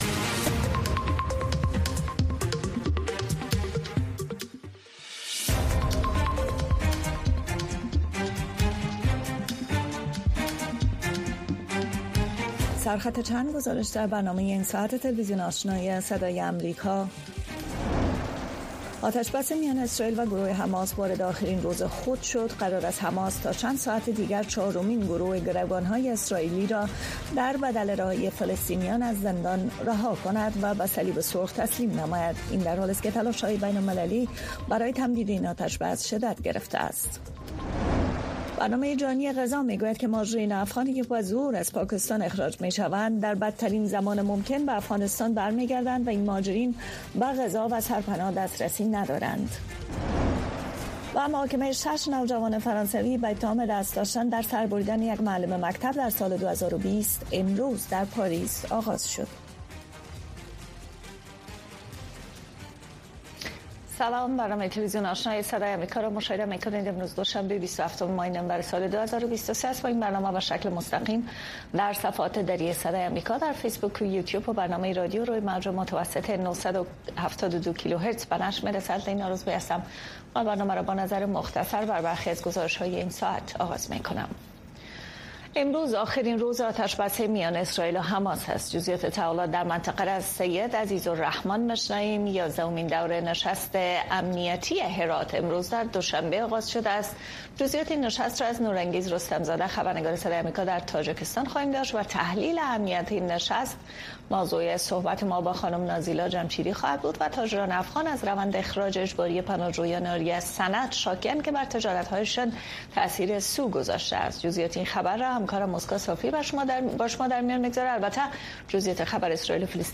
برنامه خبری آشنا